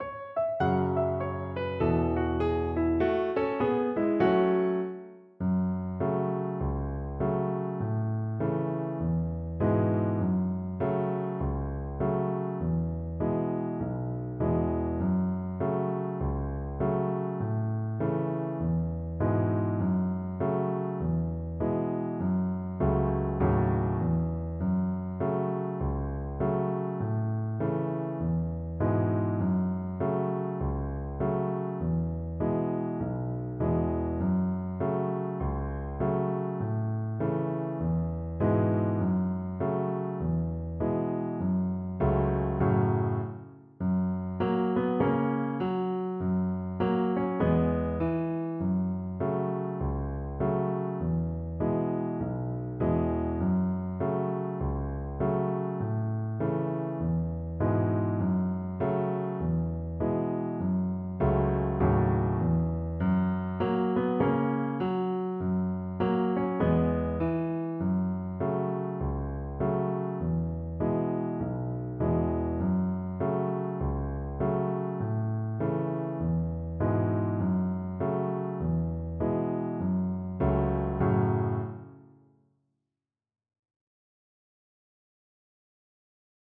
Play (or use space bar on your keyboard) Pause Music Playalong - Piano Accompaniment transpose reset tempo print settings full screen
Violin
F# minor (Sounding Pitch) (View more F# minor Music for Violin )
With a swing = c.50
2/2 (View more 2/2 Music)
Hornpipe Music for Violin